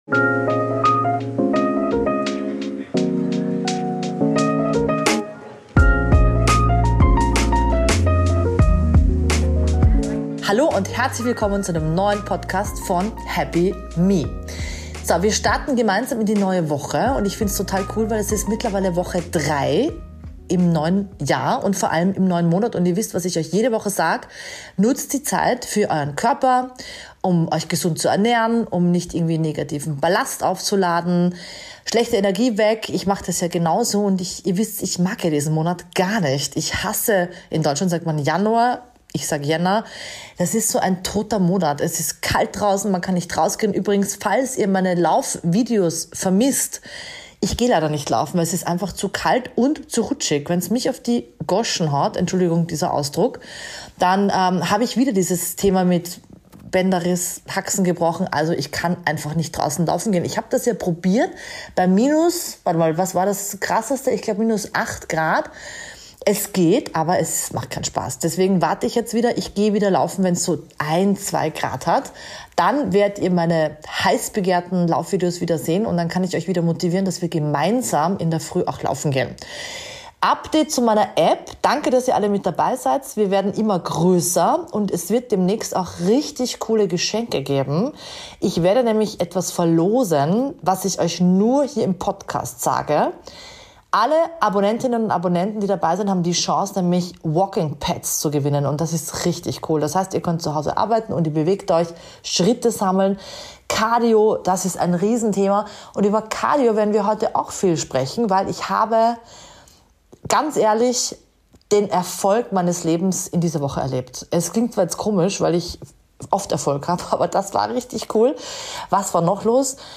Beschreibung vor 2 Monaten Heute ist eine ganz besondere Folge – denn zum ersten Mal habe ich einen Gast im Studio!